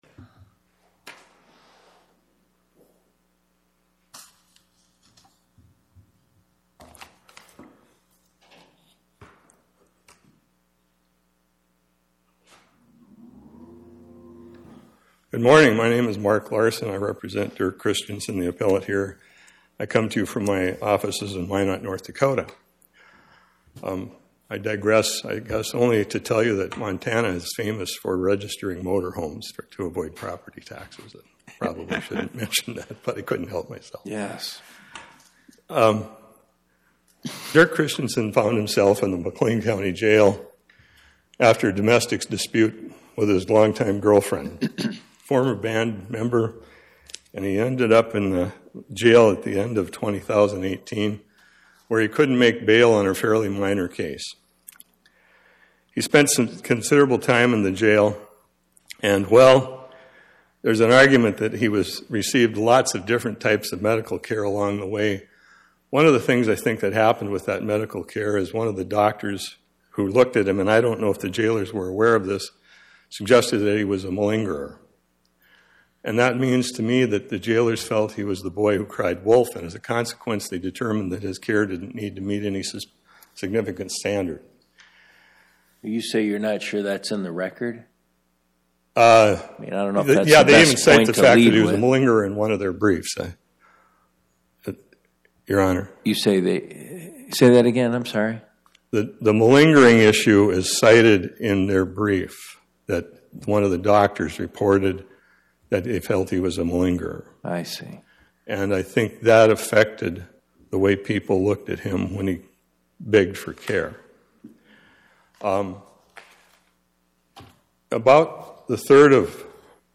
Oral argument argued before the Eighth Circuit U.S. Court of Appeals on or about 02/10/2026